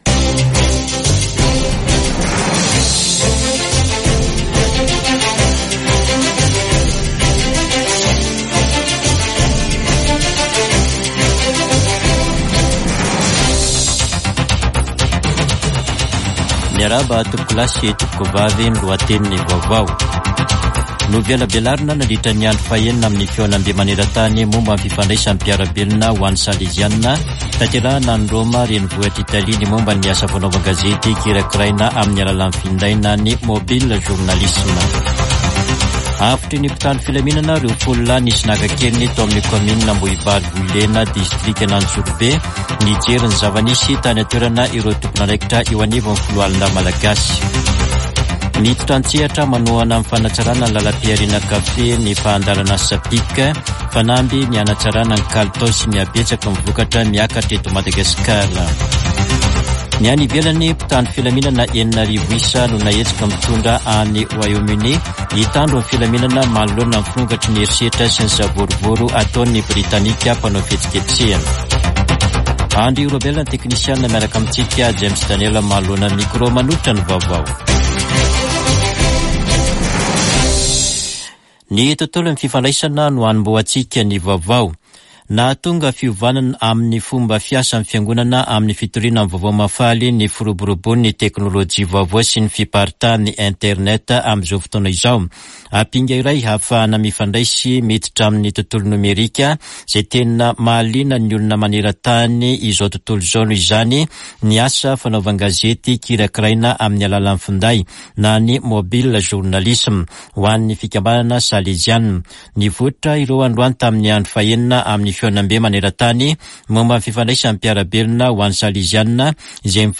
[Vaovao hariva] Talata 6 aogositra 2024